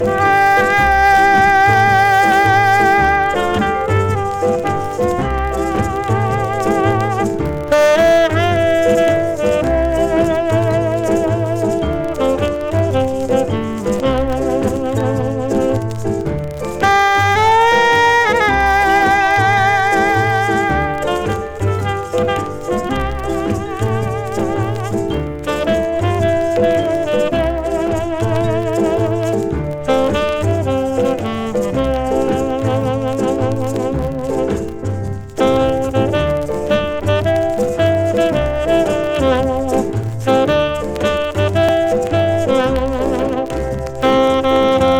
Jazz, Rhythm & Blues　USA　12inchレコード　33rpm　Mono